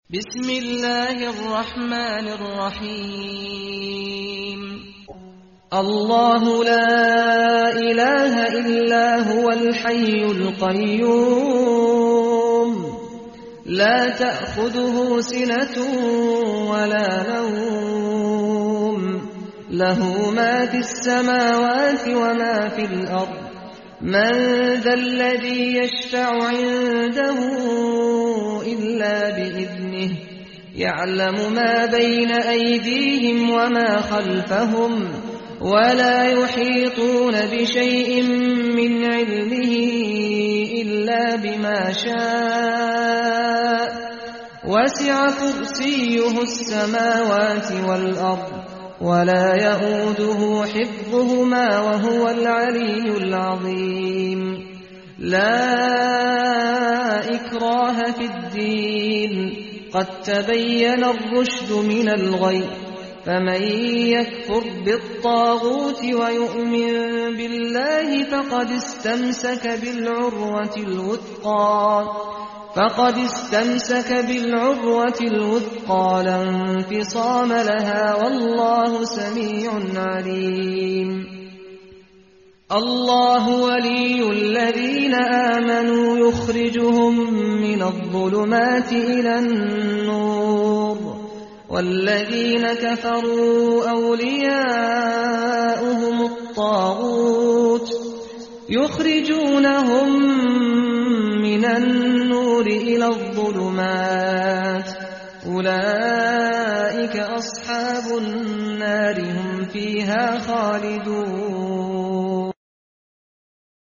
اگر علاقه‌مند به گوش دادن متن آیت الکرسی هستید و دوست دارید صوت آیه الکرسی قاریان برتر را بشنوید با این بخش همراه شوید زیرا بهترین فایل‌های صوتی را برای شما آماده کرده‌ایم.
آیه الکرسی صوتی سعد الغامدی
فایل-صوتی-آیت-الکرسی.mp3